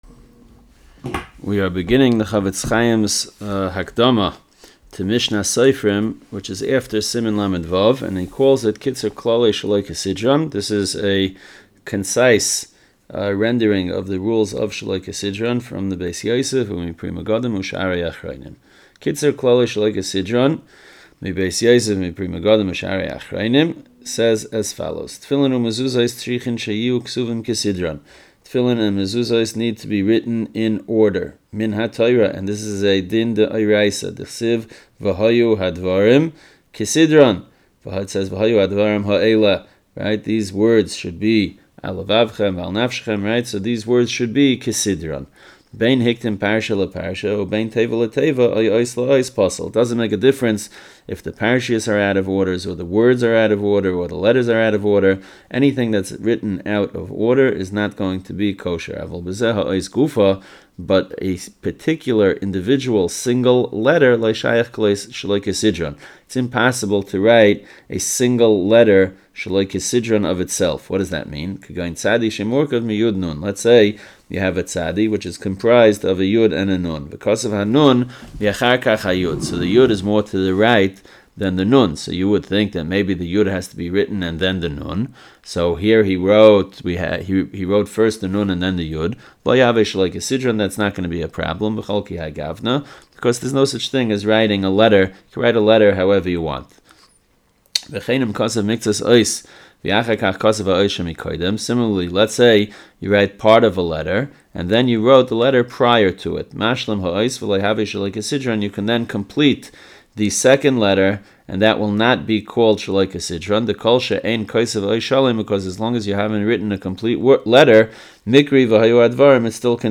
Audio Shiurim - The STa"M Project | Kosher-Certified Mezuzos, Tefillin & STa”M